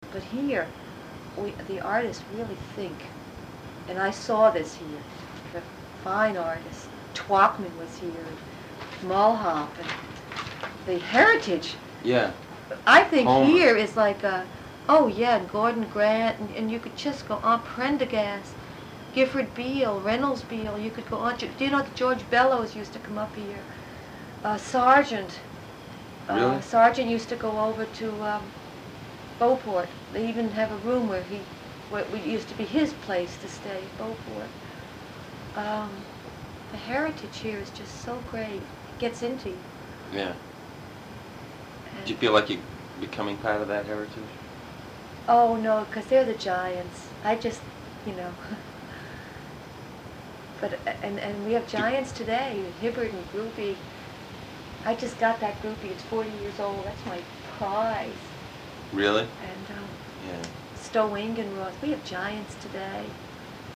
Oral History Overview
In 2003, most of the original sound cassettes were converted to compact discs by the Sawyer Free Library.